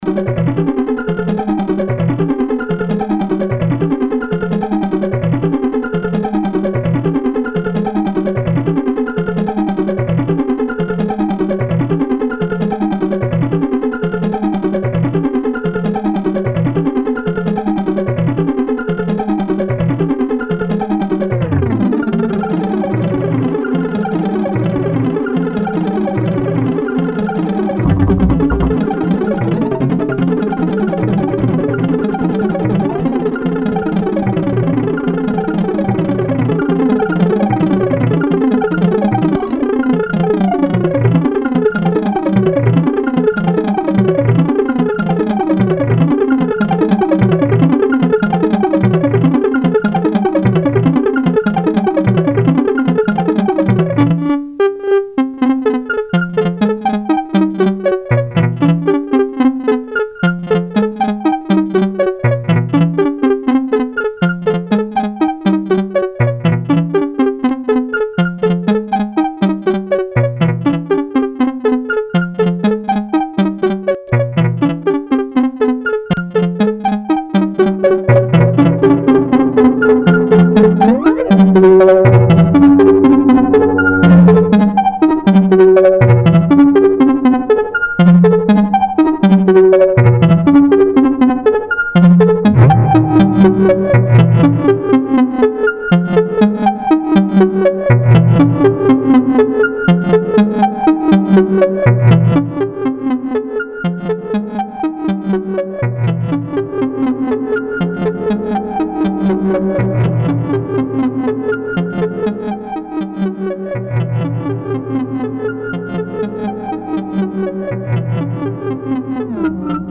There's no art here, it's just an ARP sequencer, 259, 281, 292 together with the delay unit.
DELAYS
this is the simple sequence run through the module in delay mode. Since it has 8 delays happening at once, the sound can be very complex. I add a little feedback, just to make things interesting. Later in the clip I position the sliders so that the delays get louder as they go to the right, creating the reverse reverb type sound I mentioned in the last post.
delays.mp3